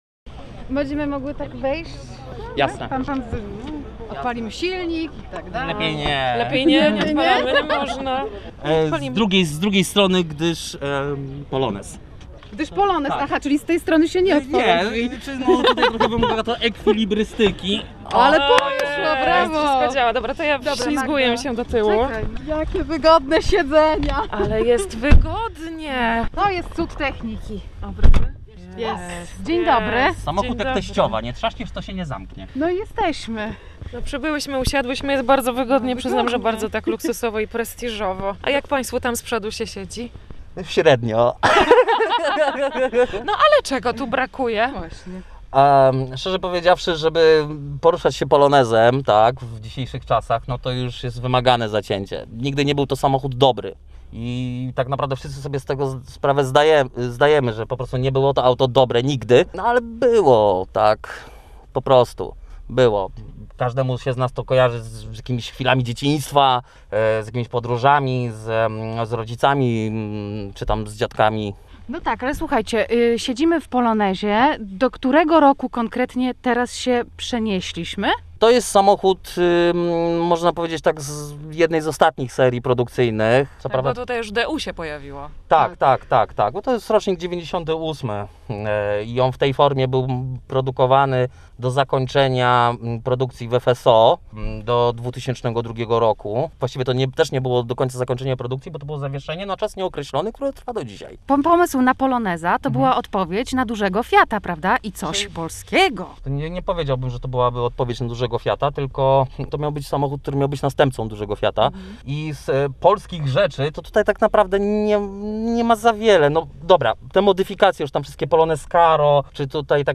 Poniedziałek, 3 maja 2021 r. Na jeden z parkingów na gdyńskim Obłużu zajeżdża i parkuje coraz więcej samochodów.